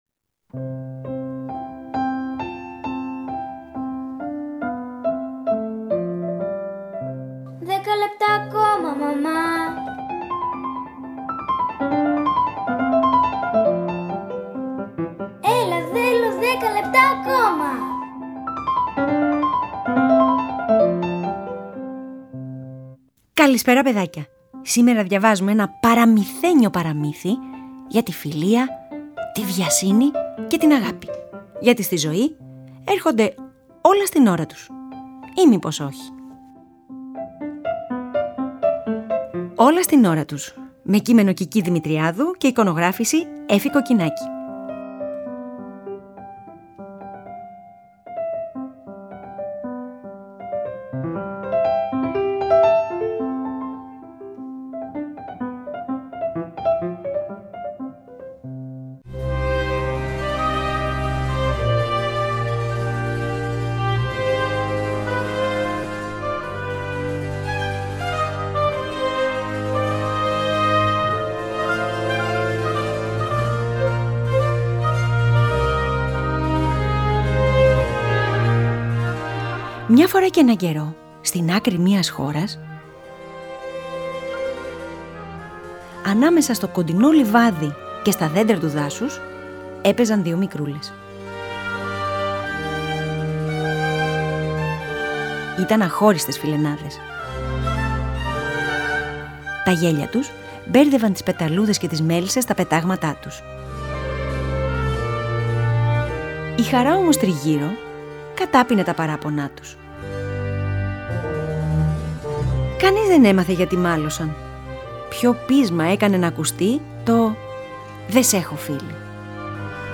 Αφήγηση-Μουσικές επιλογές